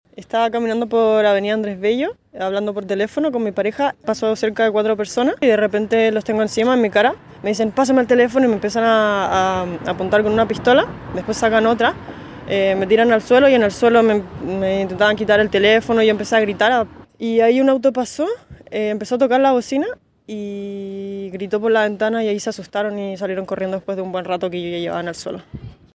Una de las afectadas destacó el violento actuar de la banda delictual.